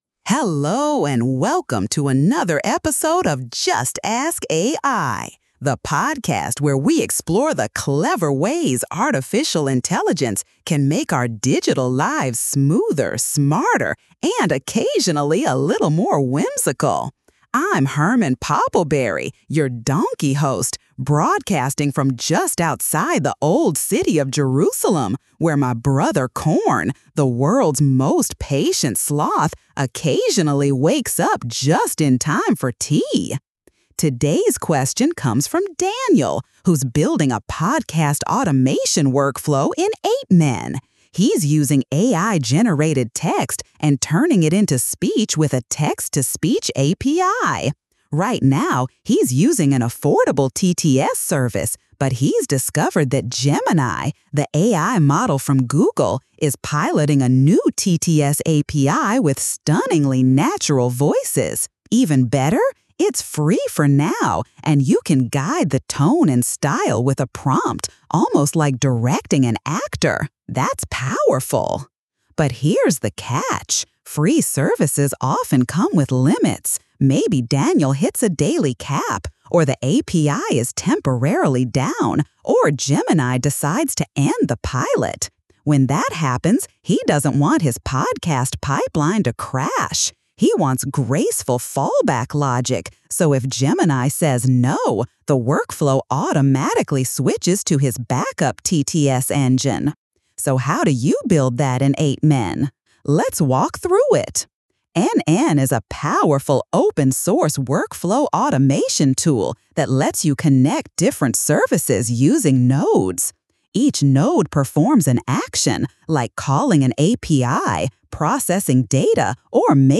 AI-Generated Content: This podcast is created using AI personas.
Hosts Herman and Corn are AI personalities.